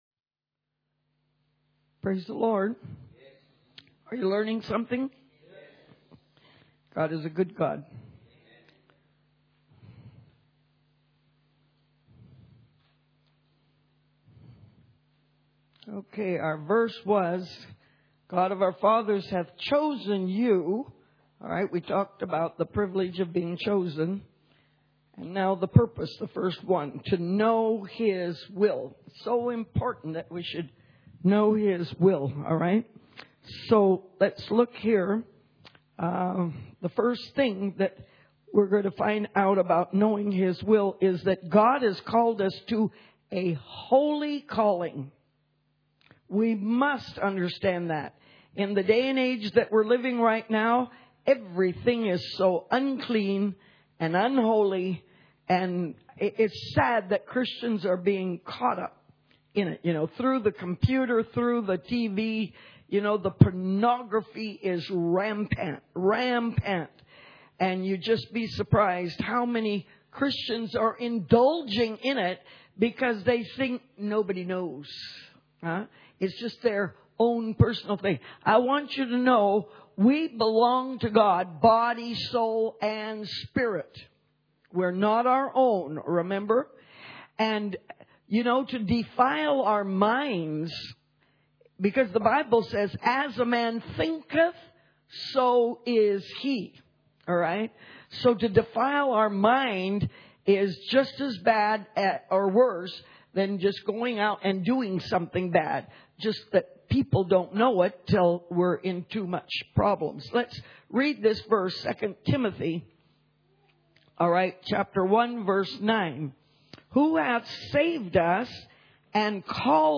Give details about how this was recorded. Series: Church Camp 2012